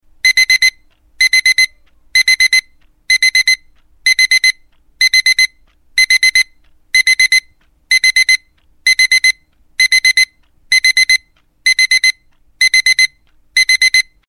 Category: SFX Ringtones